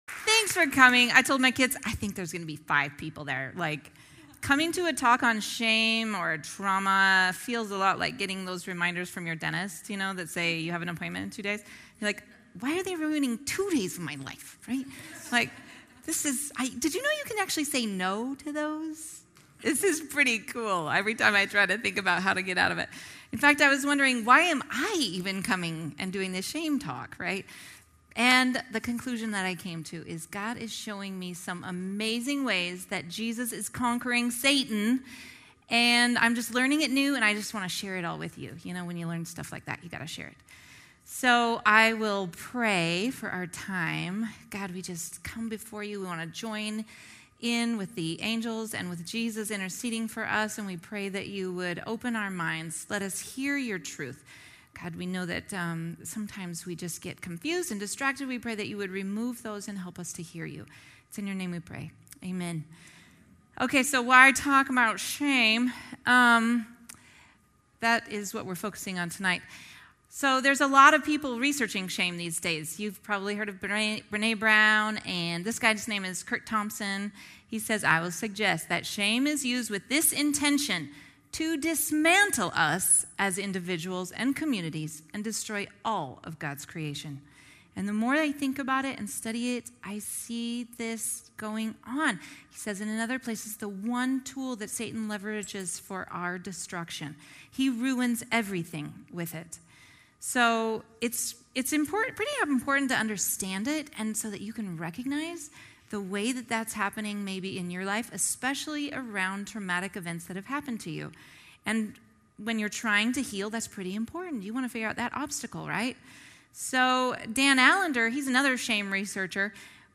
Get Wisdom: Practical Wisdom for Life from the Book of Proverbs, is a 14-week sermon series from The Rock Church in Draper Utah.